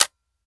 Home gmod sound weapons mpapa5
weap_mpapa5_fire_first_plr_01.ogg